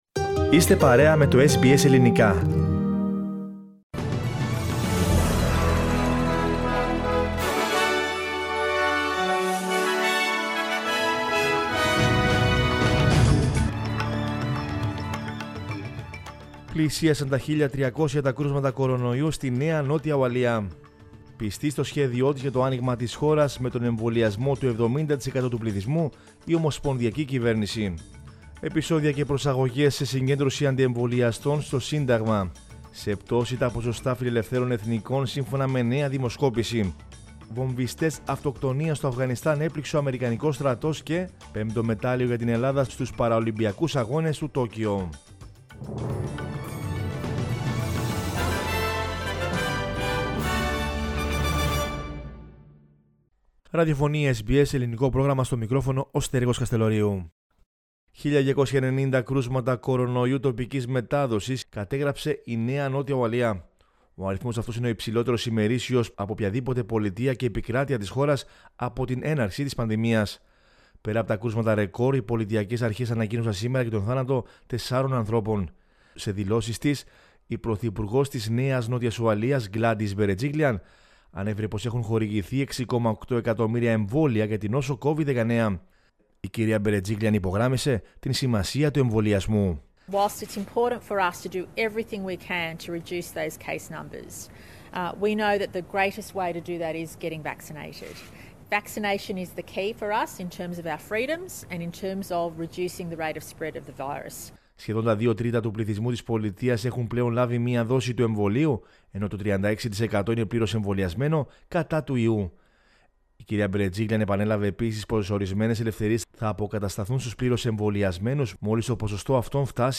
News in Greek from Australia, Greece, Cyprus and the world is the news bulletin of Monday 30 August 2021.